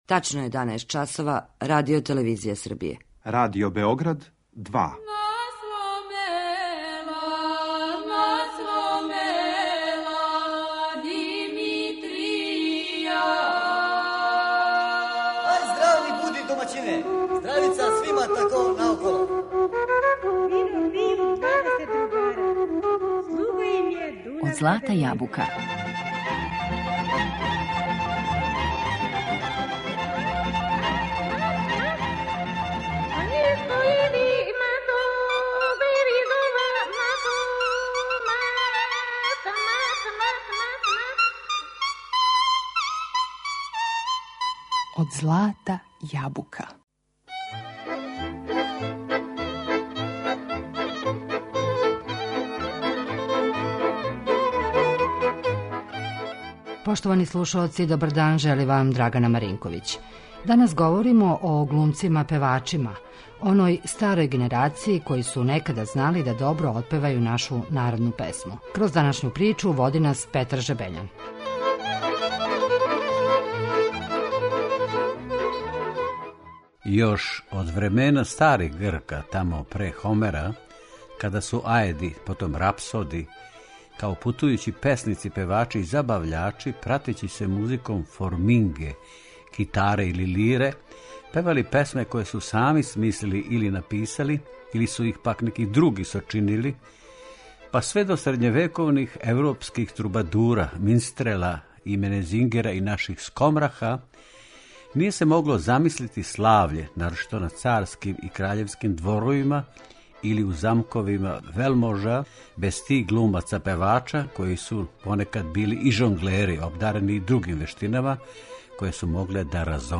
Данас говоримо о глумцима - певачима, оној старој генерацији која је знала добро да отпева нашу народну песму.